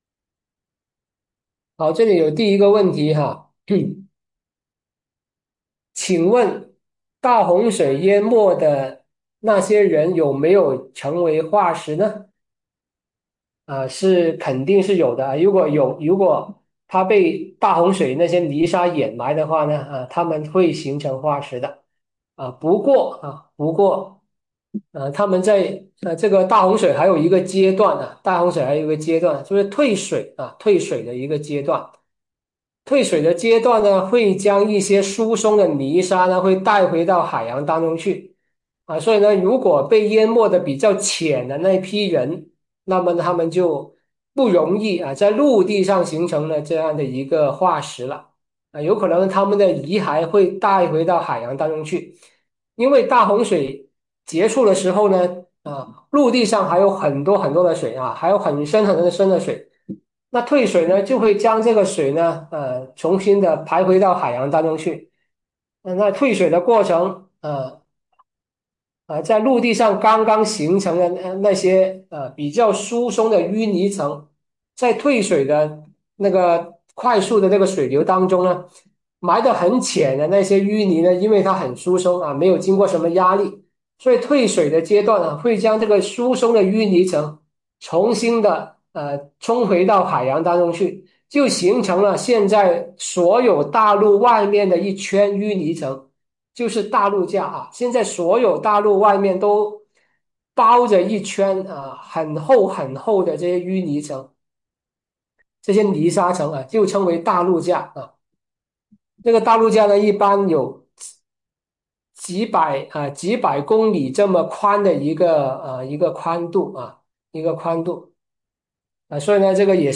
《挪亚洪水的证据》讲座直播回放